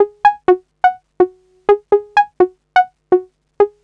cch_synth_notesyn_dry_125_Gb.wav